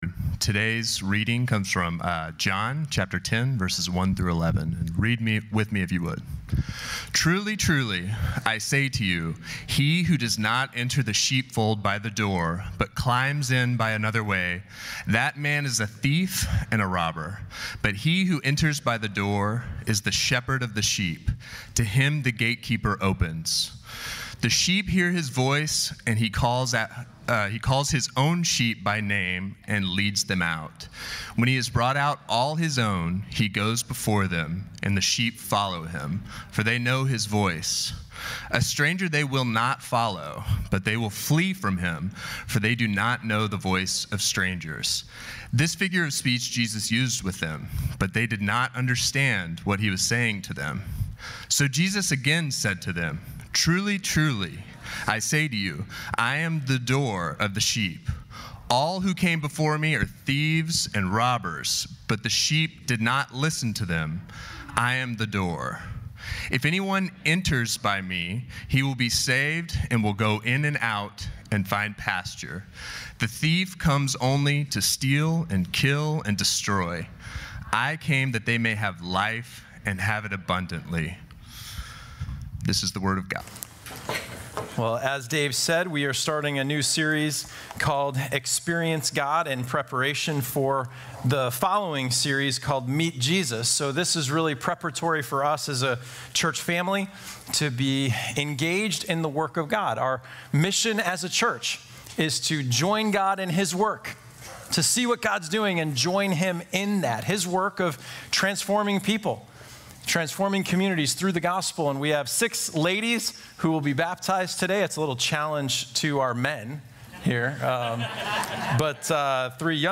This Sunday will be a special baptism service with several people publicly professing their faith in Jesus and commitment to Him.